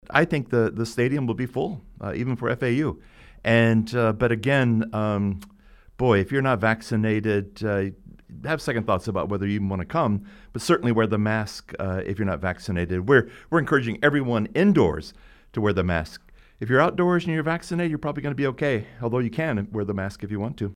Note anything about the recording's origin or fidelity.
On Friday, he came on air to discuss how the university is managing COVID-19, Oklahoma and Texas joining the SEC, and fan questions about athletic revenue and more.